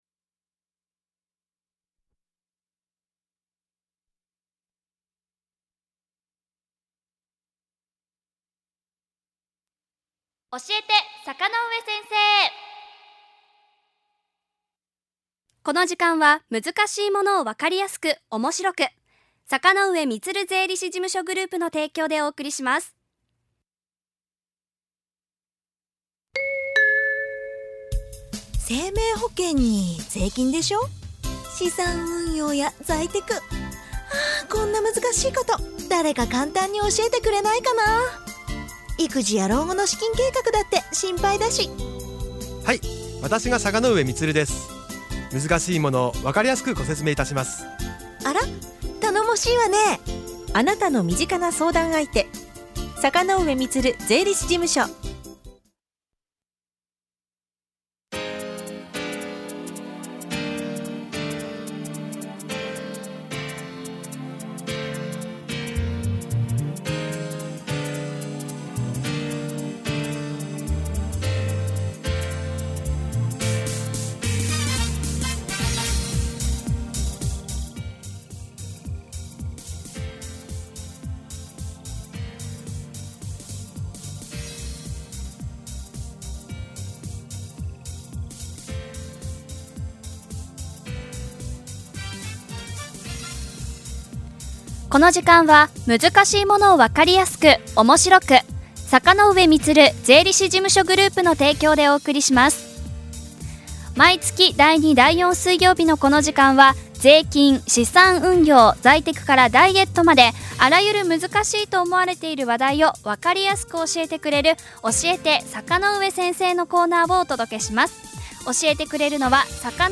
この音声は、5月23日のラジオたかおか放送内容です。